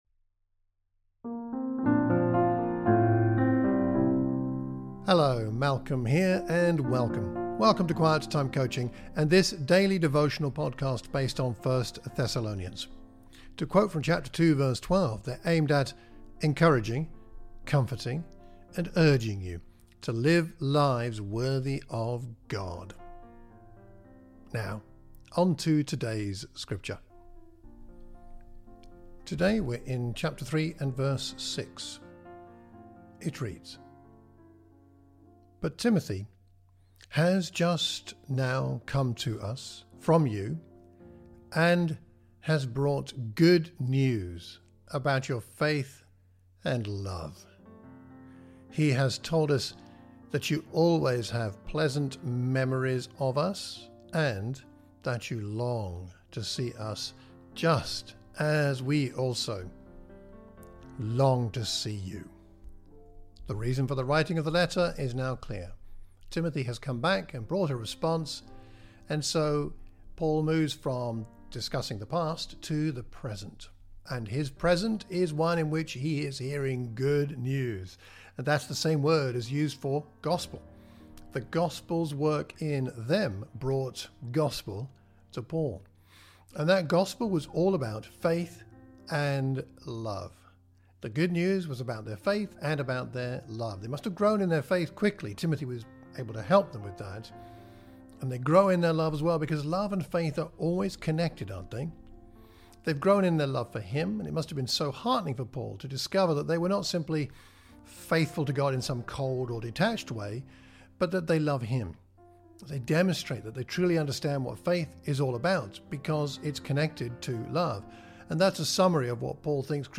You have found a daily devotional podcast on 1 Thessalonians. These recordings accompany the teaching and preaching series for the Thames Valley and Watford churches of Christ.